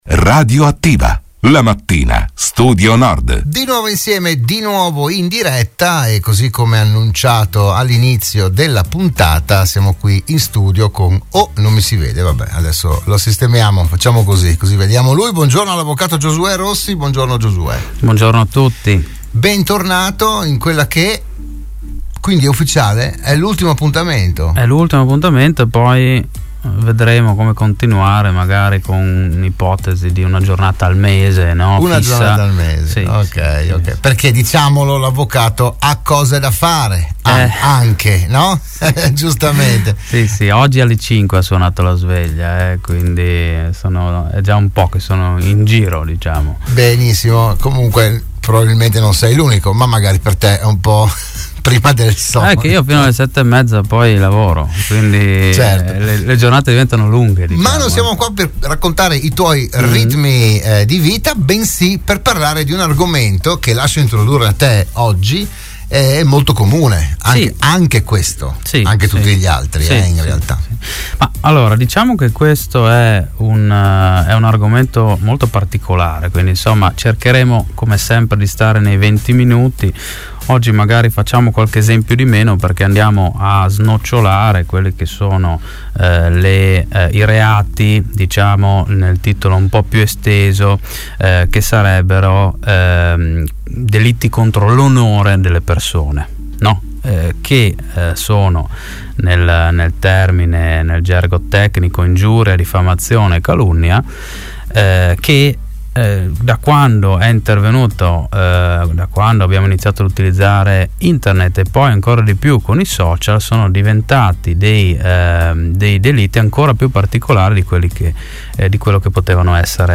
in onda ogni giovedì mattina all’interno di “RadioAttiva” su Radio Studio Nord